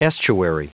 Prononciation du mot estuary en anglais (fichier audio)
Prononciation du mot : estuary